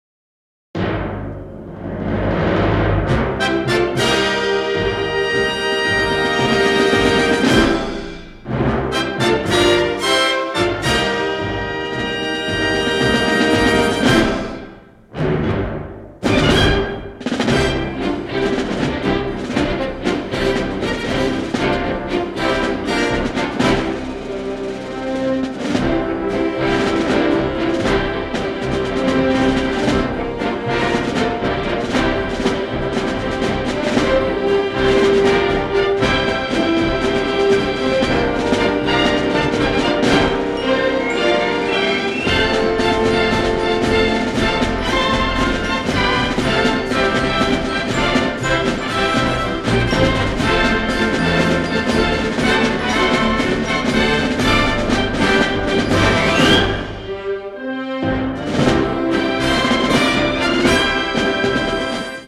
orchestral